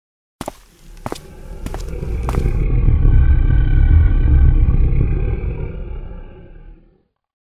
monster03.mp3